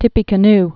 (tĭpē-kə-n)